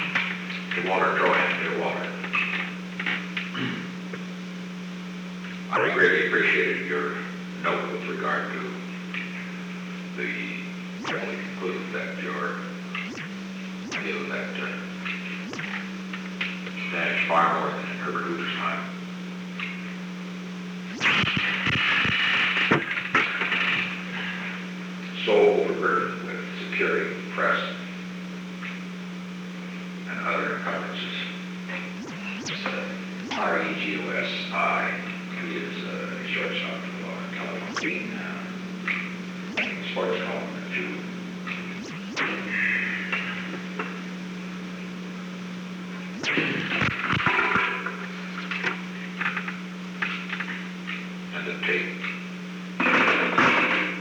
Recording Device: Oval Office
The Oval Office taping system captured this recording, which is known as Conversation 498-009 of the White House Tapes.
The President dictated a letter to Walter Trohan